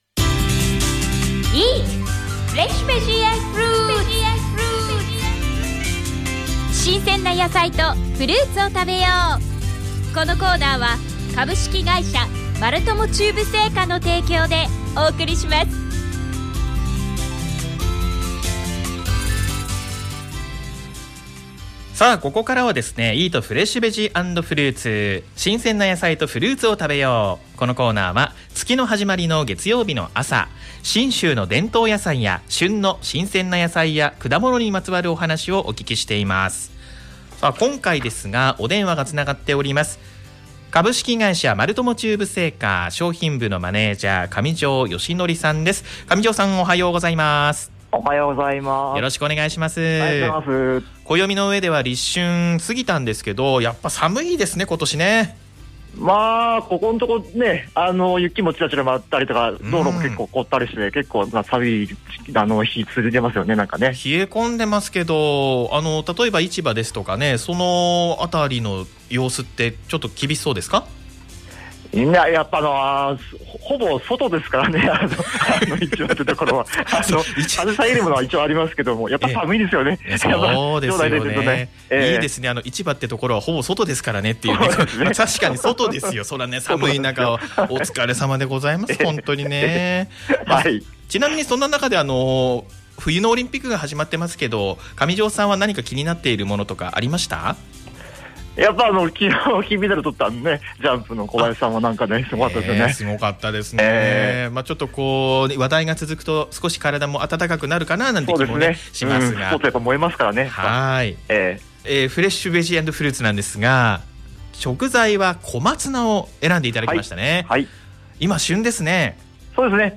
毎月第1月曜日の朝、FMまつもとで放送されています「おはよう791」の中で、8時半より丸友中部青果提供の